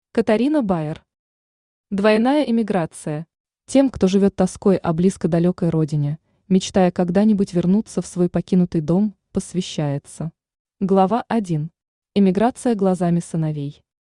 Аудиокнига Двойная эмиграция | Библиотека аудиокниг
Aудиокнига Двойная эмиграция Автор Катарина Байер Читает аудиокнигу Авточтец ЛитРес.